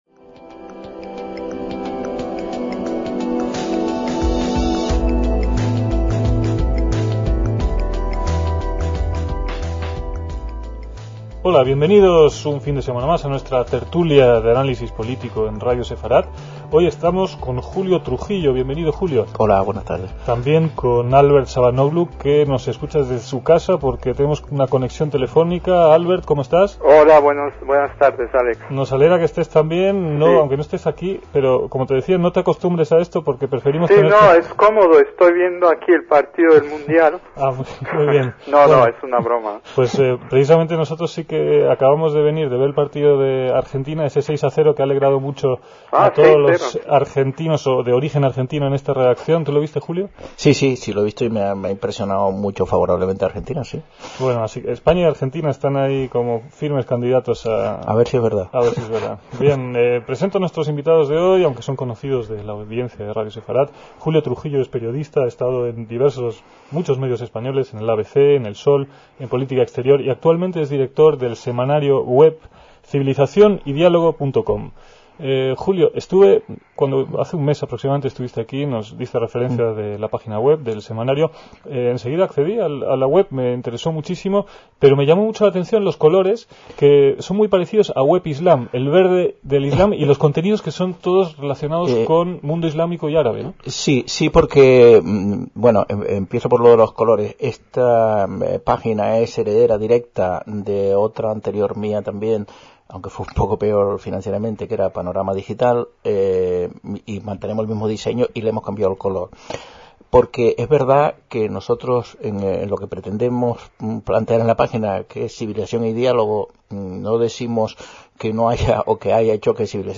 DECÍAMOS AYER (17/6/2006) - Los temas que debatieron los invitados a la mesa redonda semanal en esta emisión de 2006 fueron nuevamente cómo coinciliar la negociación con grupos terroristas con la protección a sus víctimas, y también lo que por entonces era noticia en Medio Oriente, como la visita del presidente estadounidense Bush a Irak.